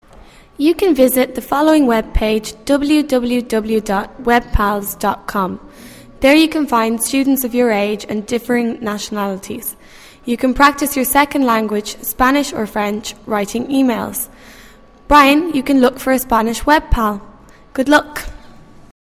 Computer science class: